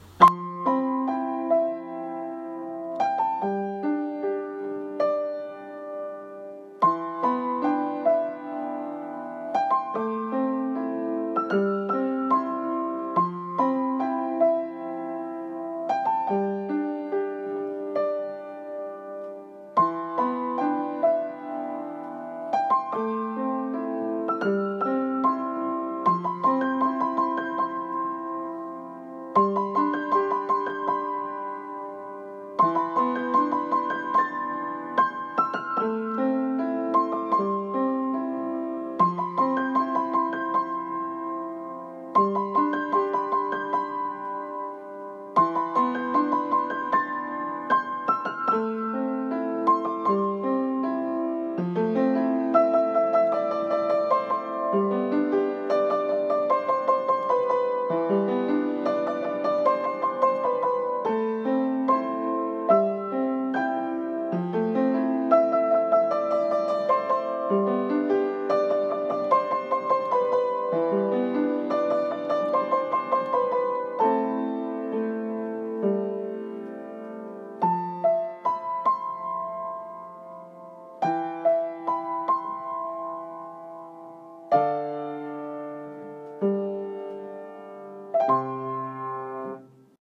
【一人声劇】最後のビデオレター